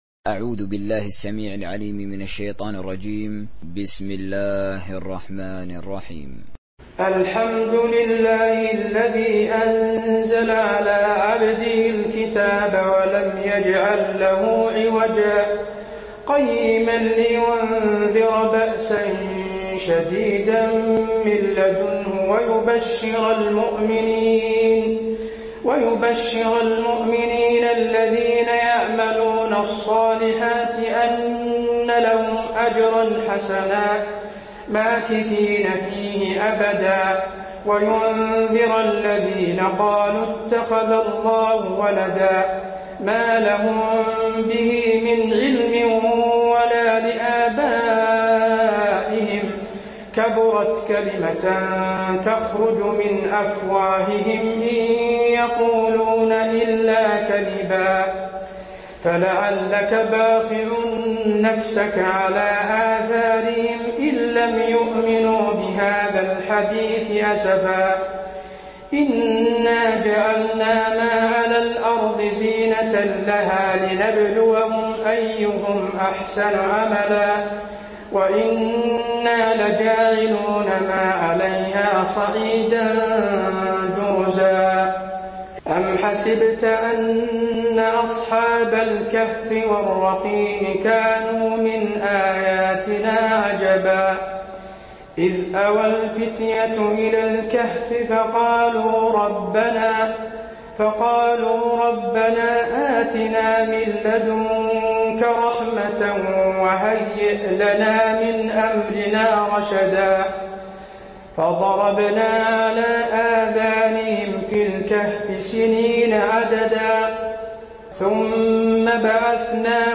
دانلود سوره الكهف mp3 حسين آل الشيخ تراويح روایت حفص از عاصم, قرآن را دانلود کنید و گوش کن mp3 ، لینک مستقیم کامل
دانلود سوره الكهف حسين آل الشيخ تراويح